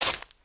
footsteps
decorative-grass-06.ogg